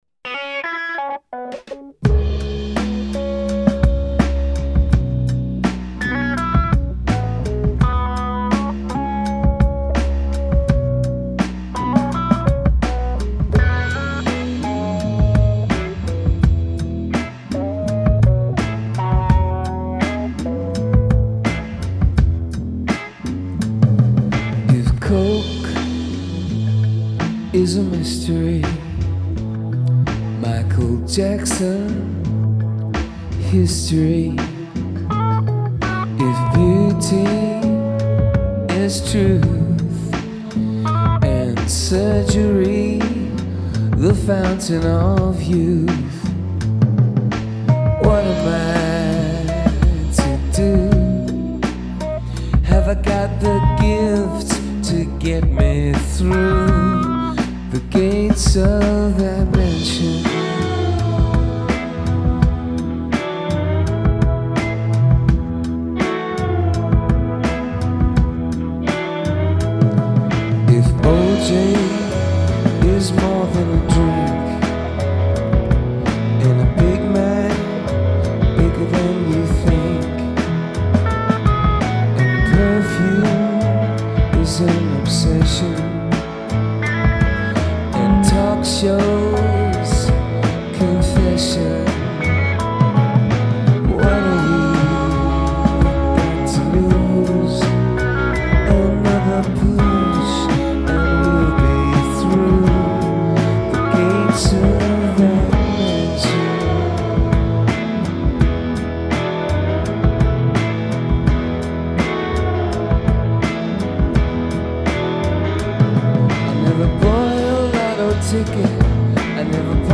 it’s carried along on a pitter patter of processed beats